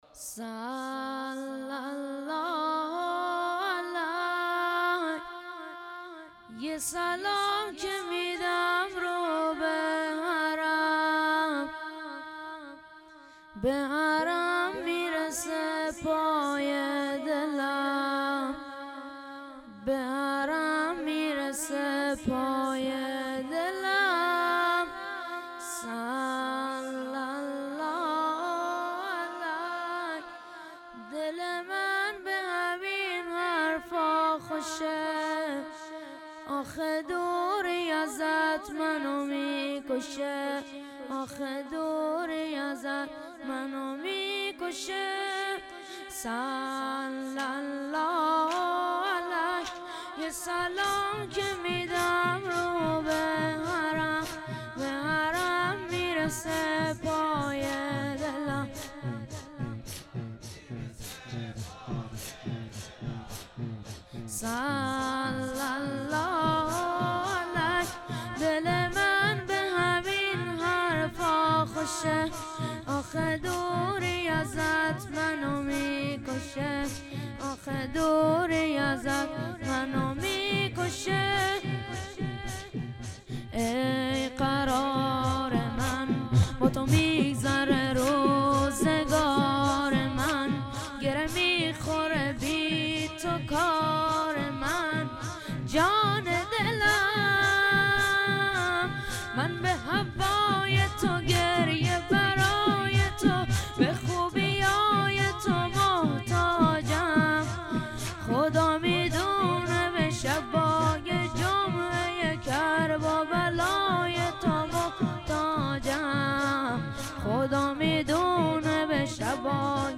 هیئت محبان الحسین (ع)